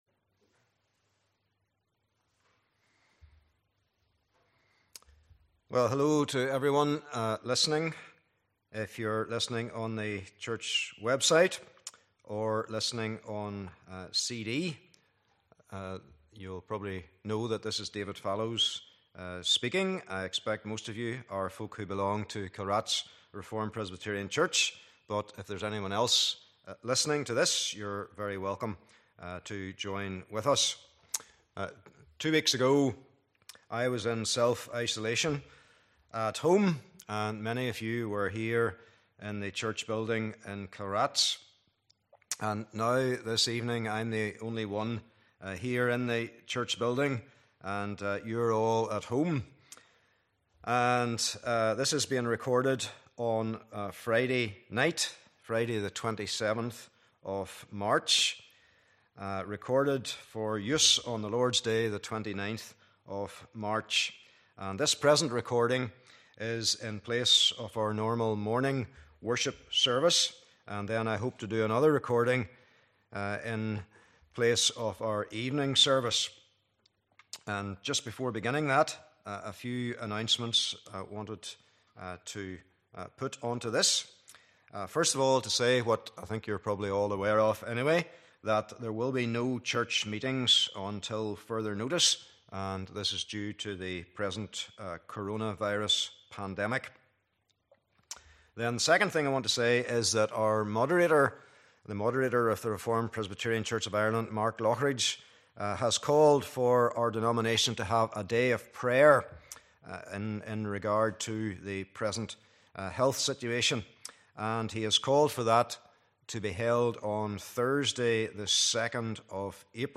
Passage: Exodus 20 : 7 Service Type: Morning Service